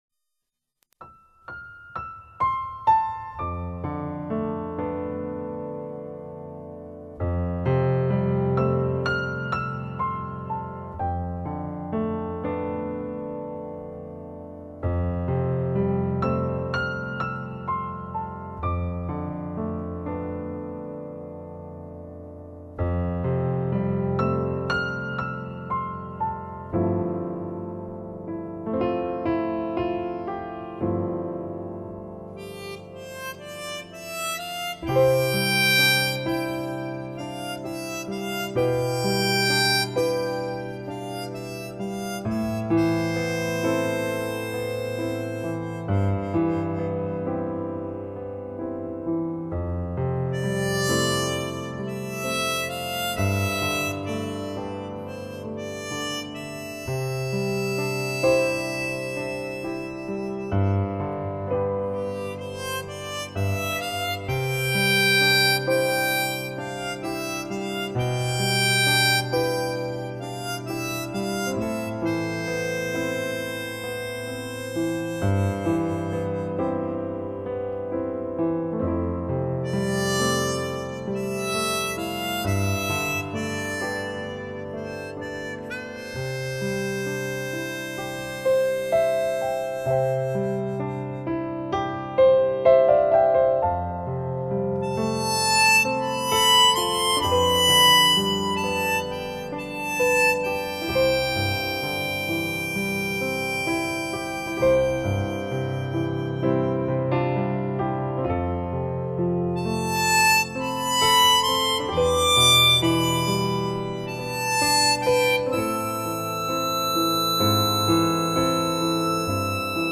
此张专辑以 钢琴与电子合成为主，音乐充满了画面色彩。